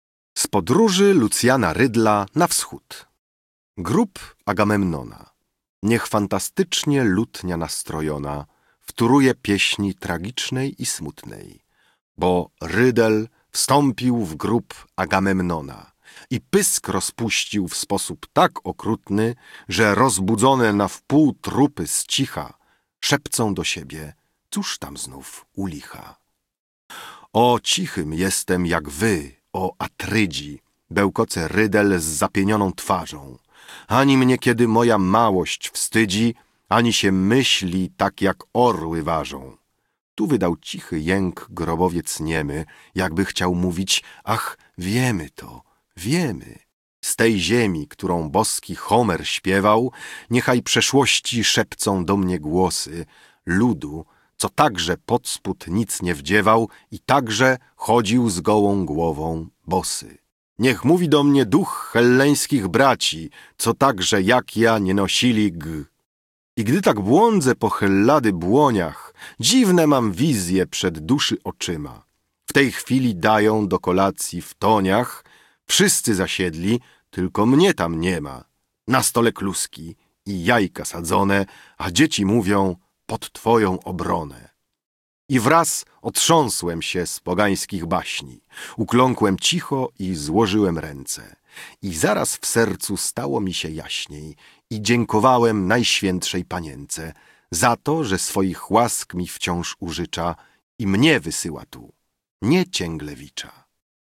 Wiersz
Audiobook